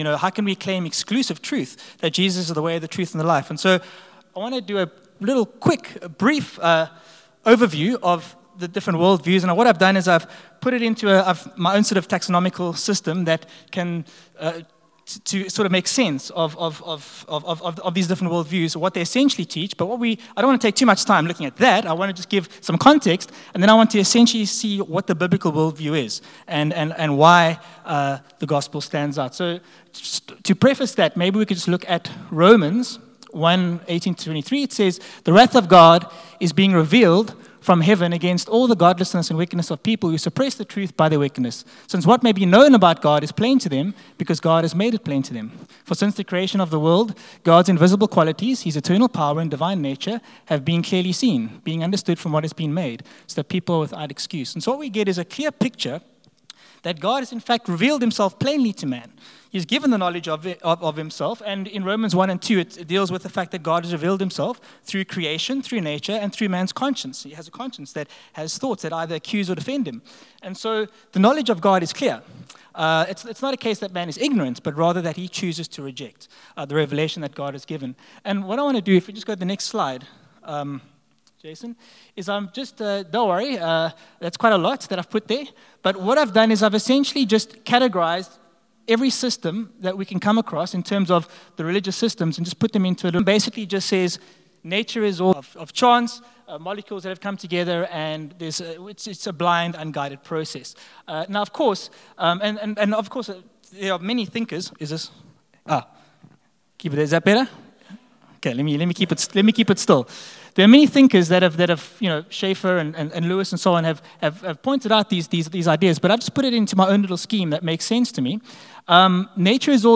Sunday Service – 13 April
Reach the Street Sermons